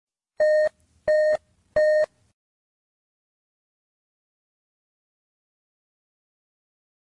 小猫1
我慢慢地缓和发声，以避免任何声带损伤。 当我缓缓进入时，有一个非常安静的高音调口哨从我的喉咙后面传来，对麦克风来说太安静了。 有时在录音时，我确实会有吱吱声和噼里啪啦的声音被拾起，但这里没有。
标签： 声音 假的 演员 配音表演 表演 人声 小猫
声道立体声